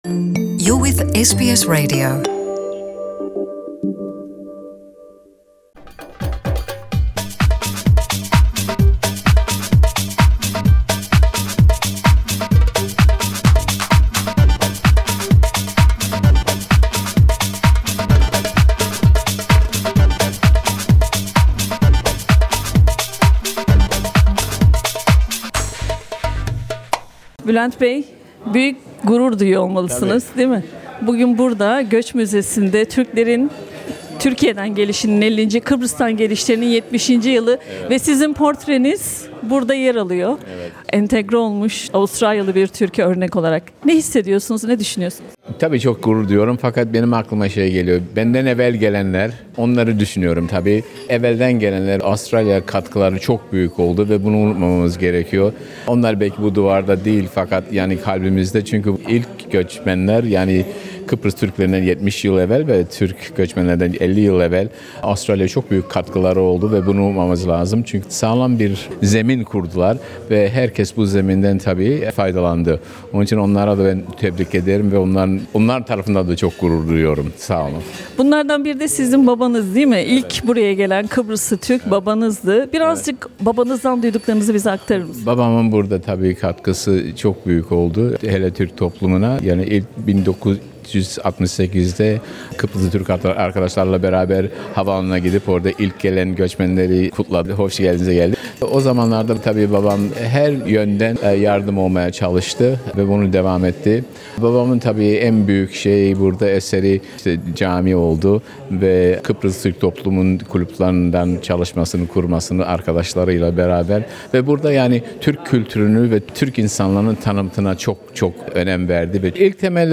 Moreland Türk Derneği'nin Türkler'in Avustralya'ya Türkiye'den gelişinin 50'nci, Kıbrıs'tan gelişinin 70'inci yılı nedeniyle düzenlediği kutlamalar kapsamında, "Kimlik" adı verilen serginin açılışı ve Sofra festivali Göç Müzesi'nde gerçekleştirildi.